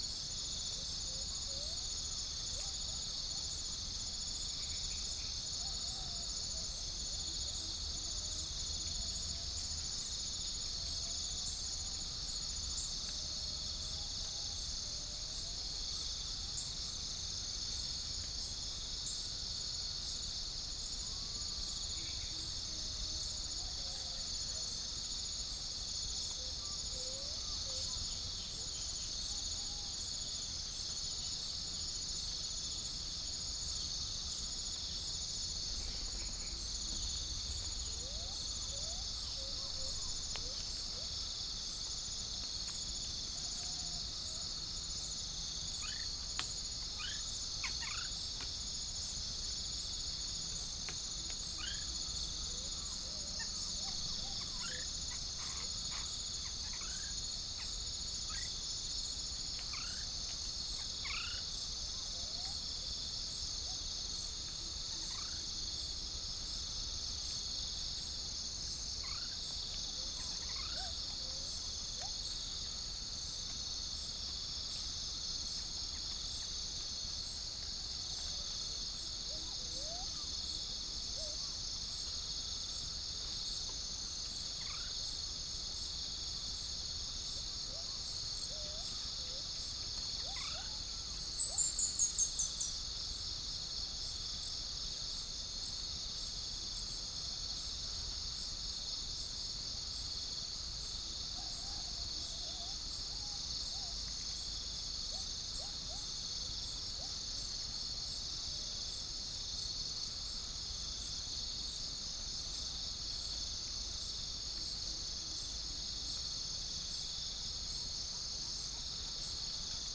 Gallus gallus
Pycnonotus goiavier
Todiramphus chloris